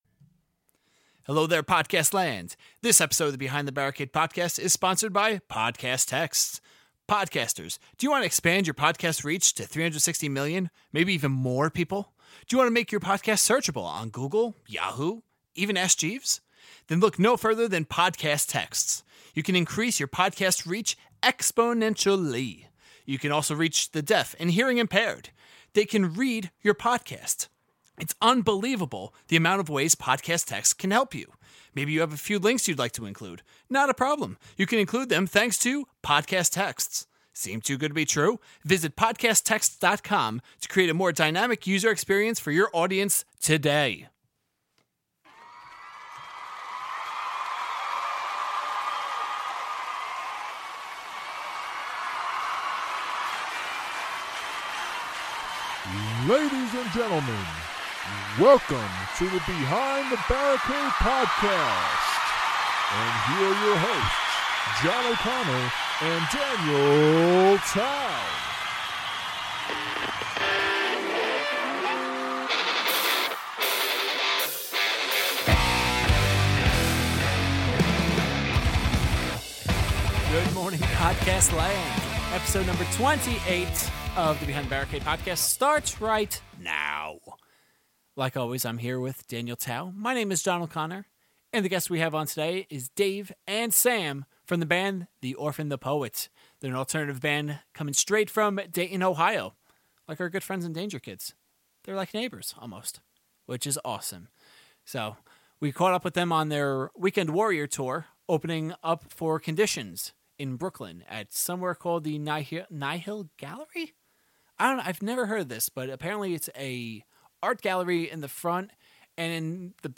Between the party in the background and the subject matter, this was a wild but extremely interesting podcast!